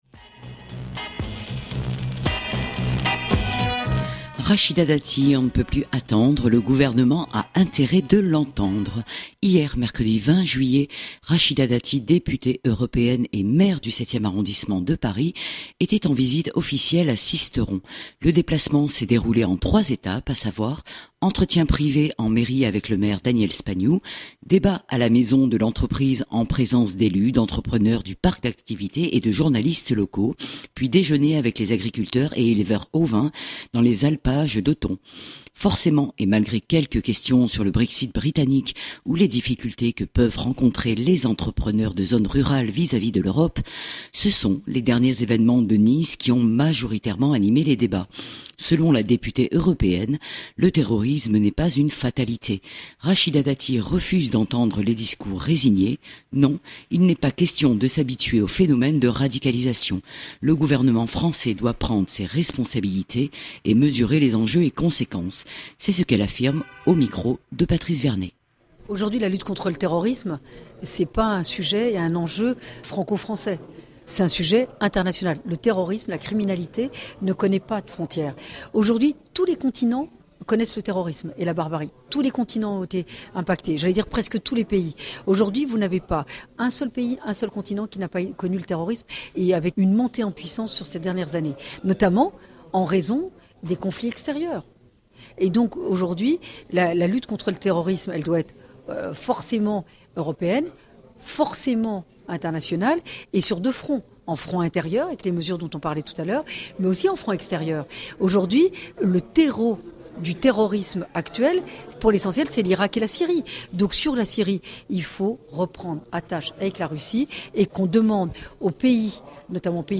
Rachida Dati refuse d’entendre les discours résignés : pas question de s’habituer au phénomène de radicalisation. Le gouvernement Valls doit prendre ses responsabilités et mesurer les enjeux et conséquences de la situation actuelle. C’est ce qu’elle affirme au micro